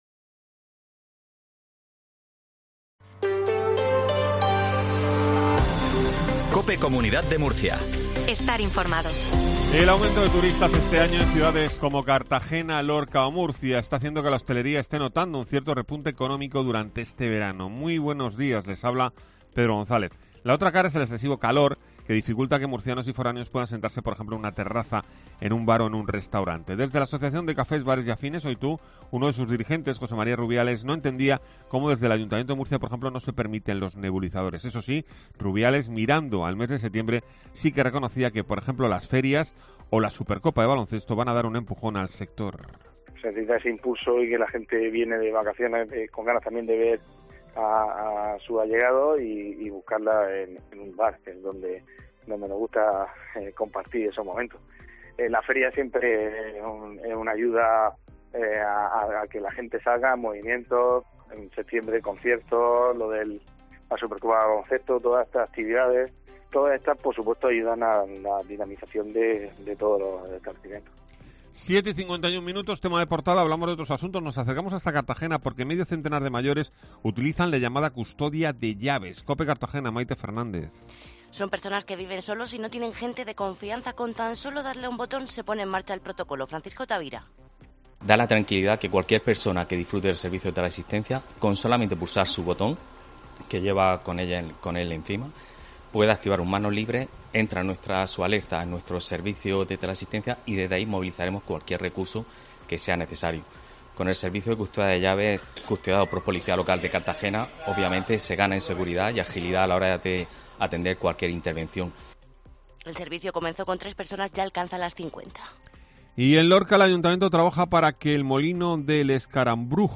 INFORMATIVO MATINAL REGION DE MURCIA 0750